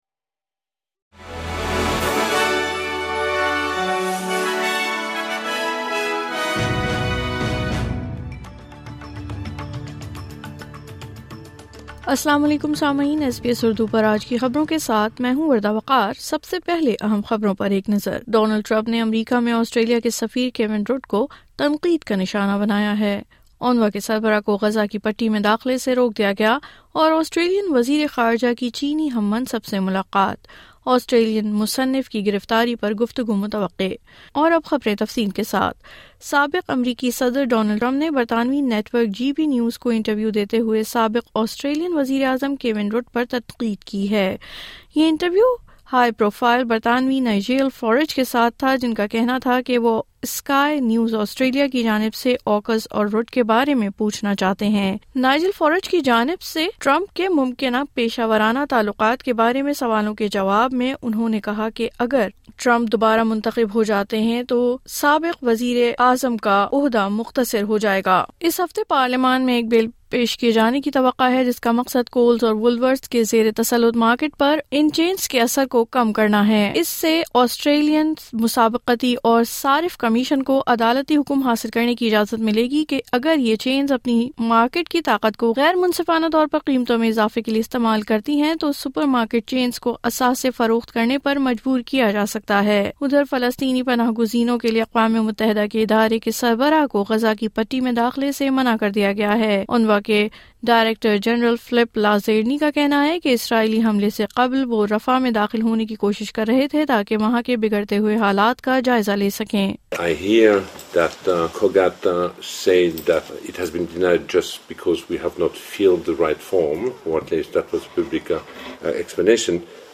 نیوز فلیش: 20 مارچ 2024: اونوا(UNWRA) کے سربراہ کو غزہ میں داخلے سے روک دیا گیا